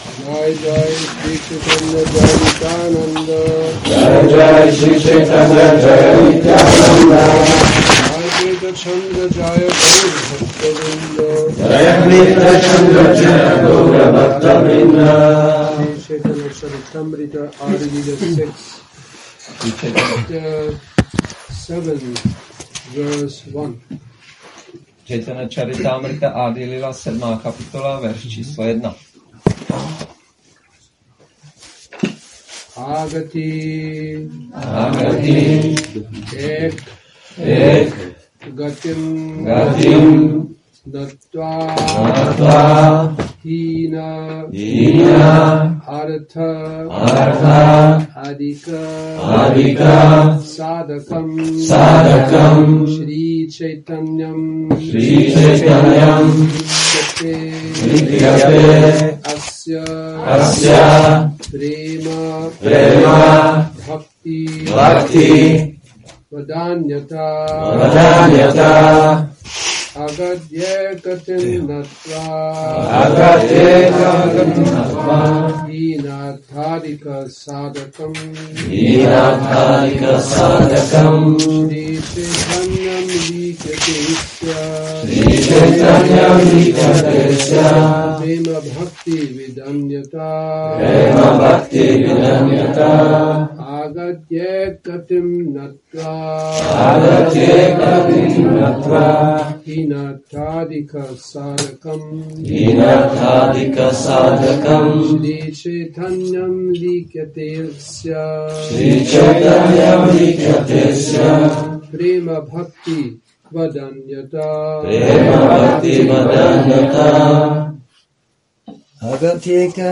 Šrí Šrí Nitái Navadvípačandra mandir
Přednáška CC-ADI-7.1